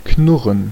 Ääntäminen
IPA: /ˈknʊʁən/ IPA: [ˈkʰnʊʁn]